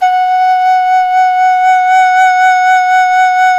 Index of /90_sSampleCDs/INIS - Opium/Partition H/DIZU FLUTE
DIZI01F#3.wav